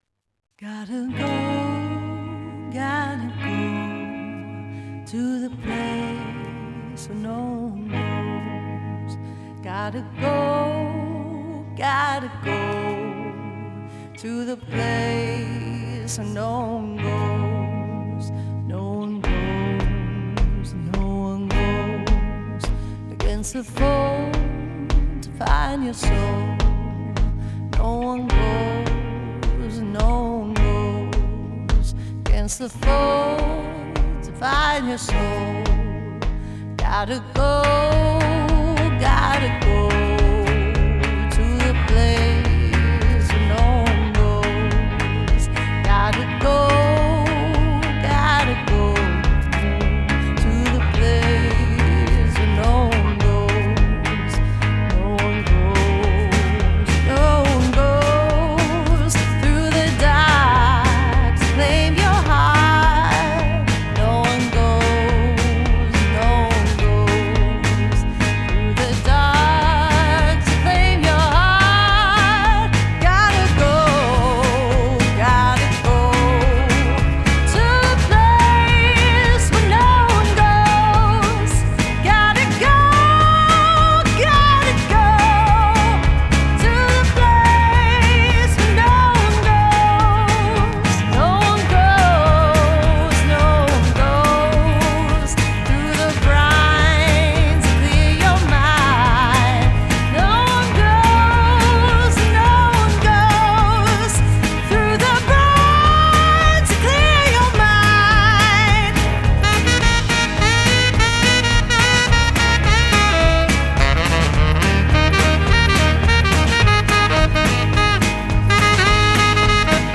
Genre: Folk Pop, Jazzy Folk